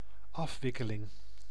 Ääntäminen
IPA: /ʁɛ.ɡlǝ.mɑ̃/